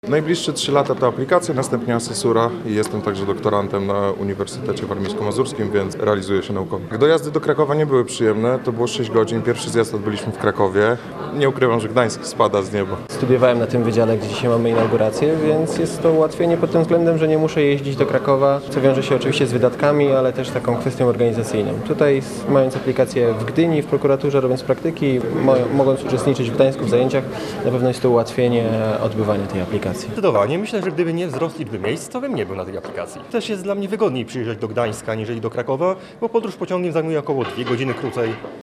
Na miejscu był nasz reporter.